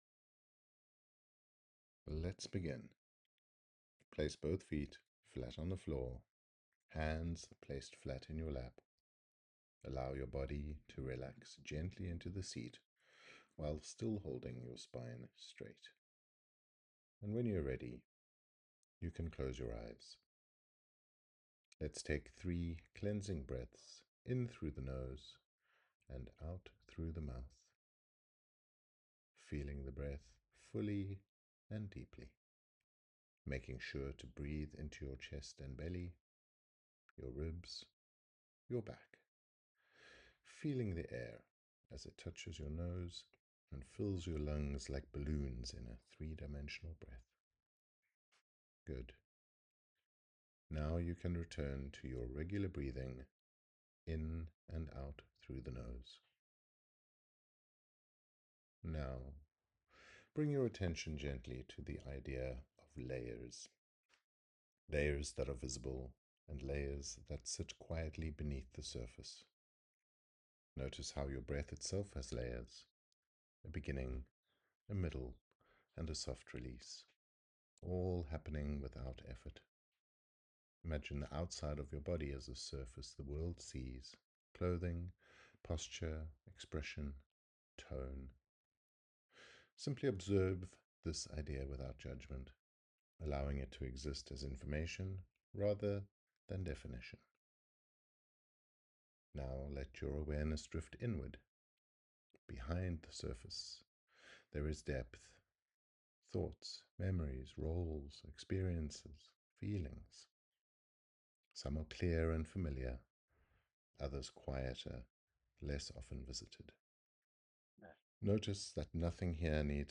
Surface & Stories Meditation
YE02-Meditation.mp3